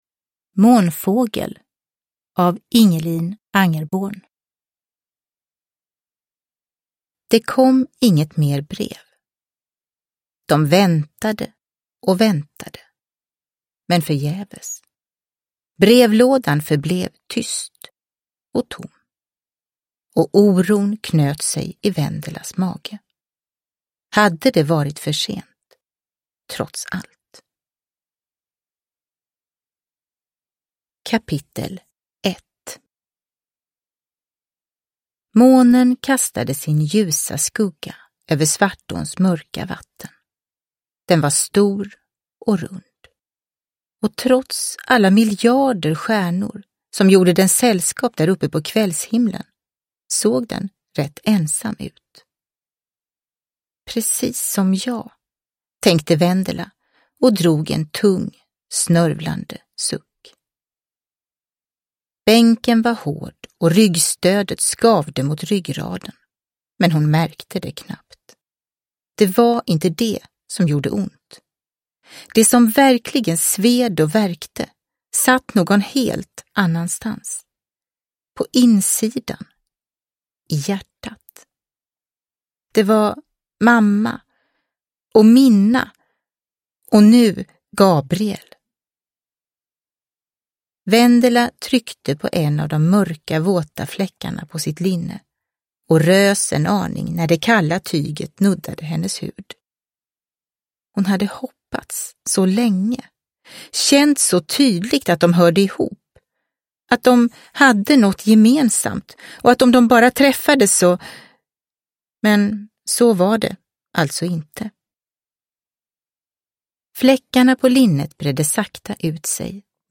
Månfågel – Ljudbok – Laddas ner